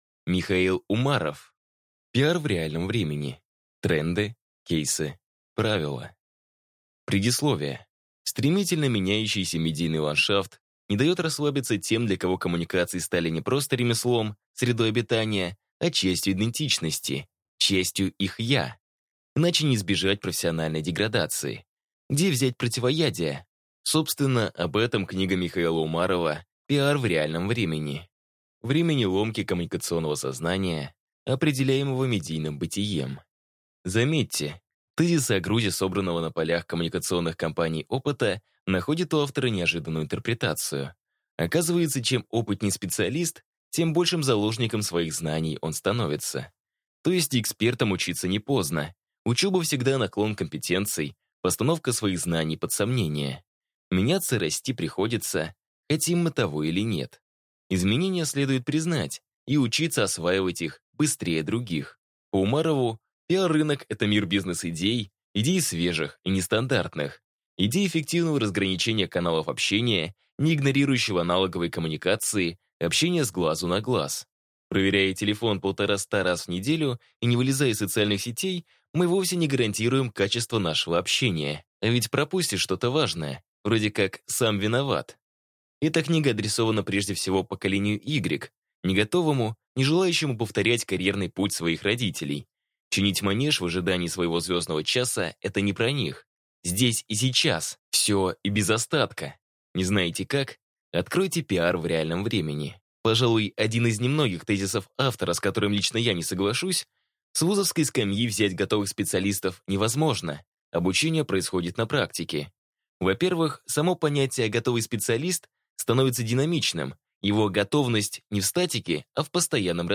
Аудиокнига PR в реальном времени: Тренды. Кейсы. Правила | Библиотека аудиокниг